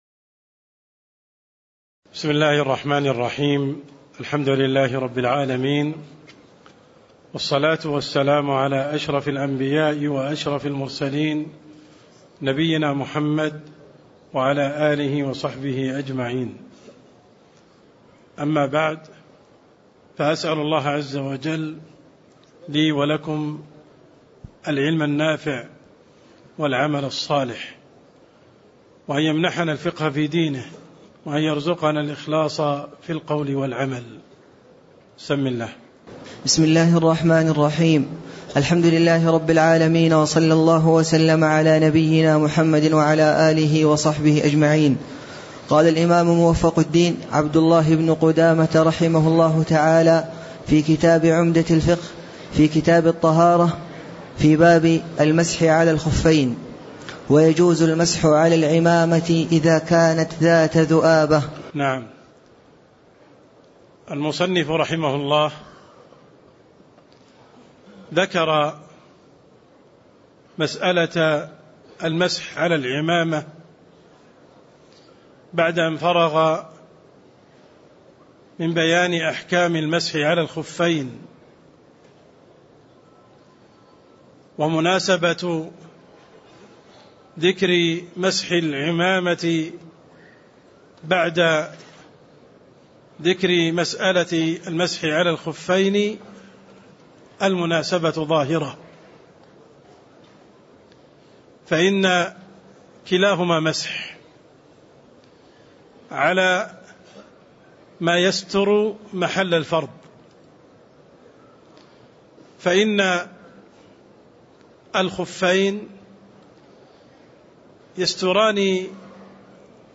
تاريخ النشر ٧ رجب ١٤٣٥ هـ المكان: المسجد النبوي الشيخ: عبدالرحمن السند عبدالرحمن السند باب المسح على الخفين (09)